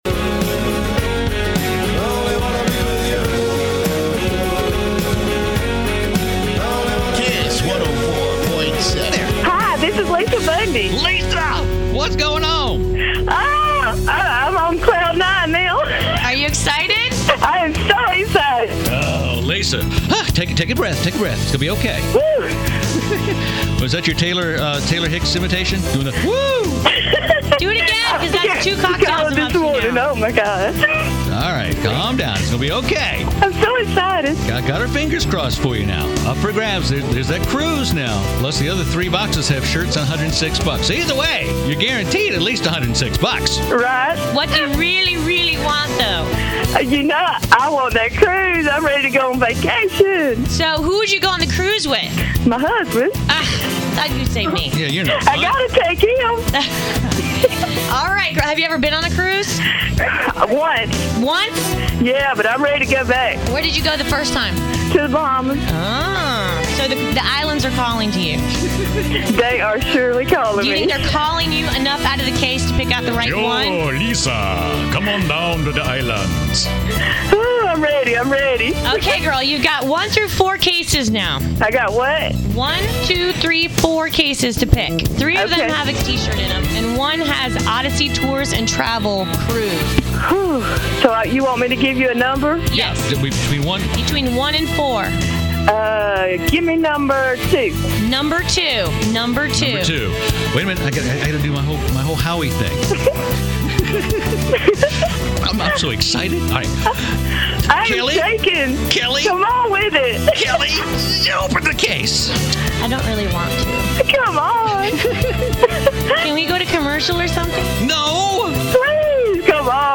XJ TALK SHOW! Ad on Kiss 104.7 FM